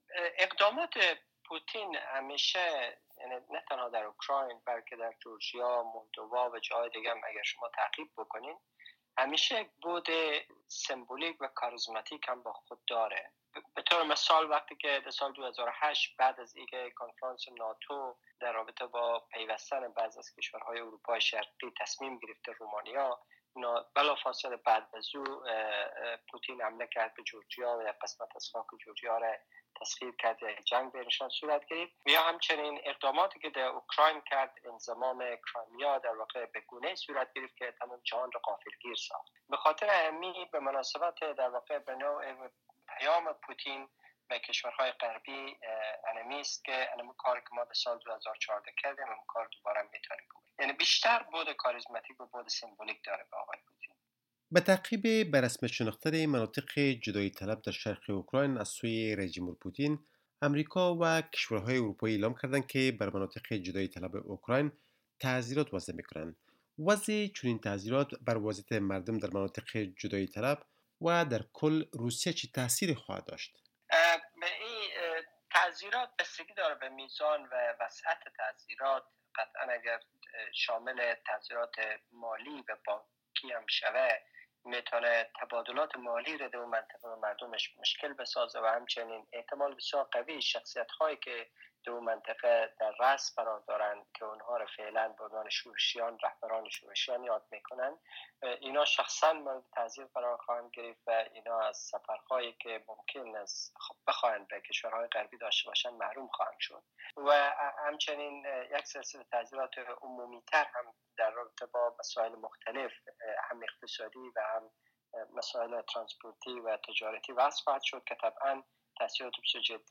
شرح کامل این مصاحبه را در اینجا دنبال کنید.